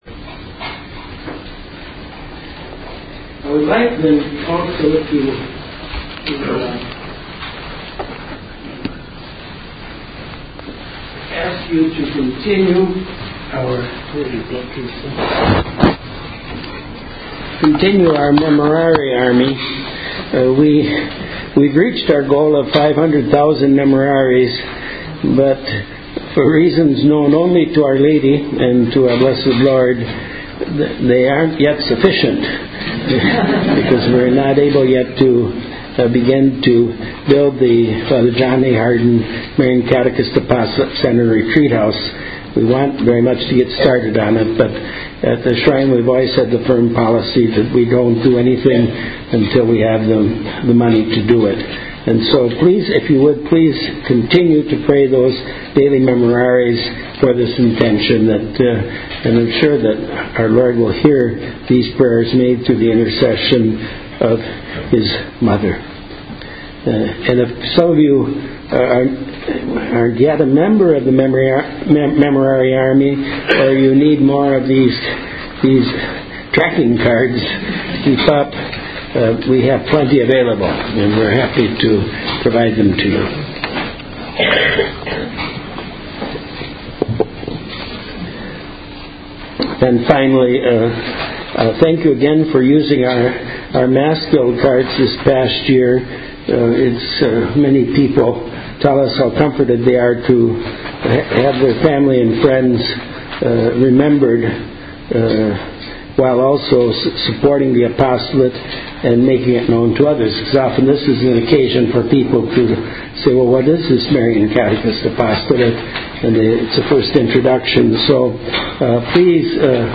Presentations by Cardinal Burke